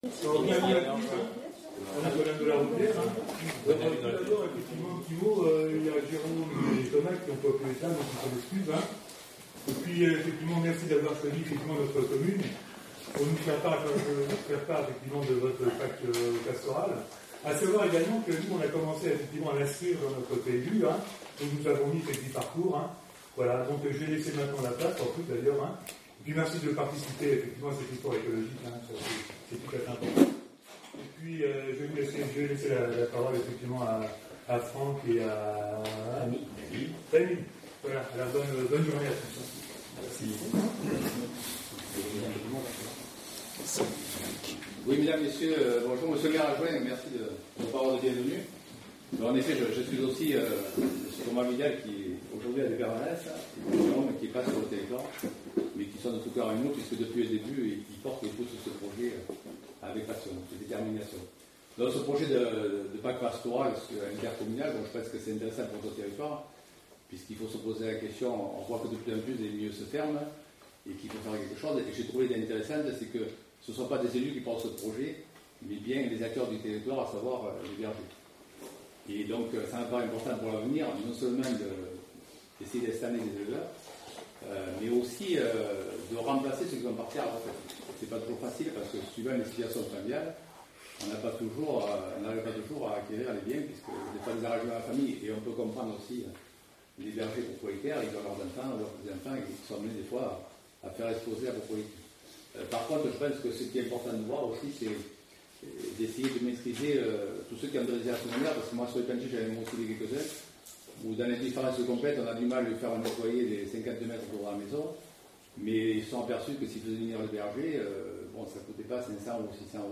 Un enregistrement audio de la pr�sentation et des questions du public a �t� effectu�, que l'on trouvera ici en 2 parties. Les interventions sont anonymes car il n'a pas �t� demand� aux personnes de se pr�senter... pr�sentation (audio 32mn)